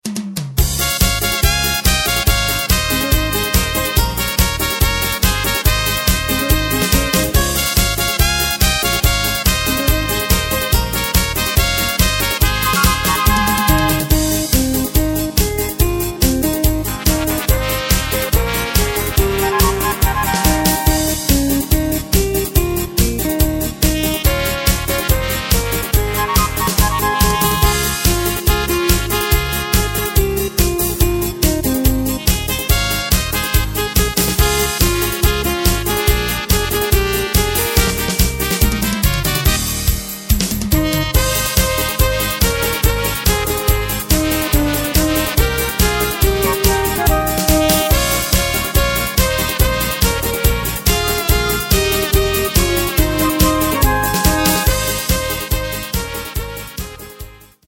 Takt:          4/4
Tempo:         142.00
Tonart:            Ab
Schlager-Polka aus dem Jahr 1996!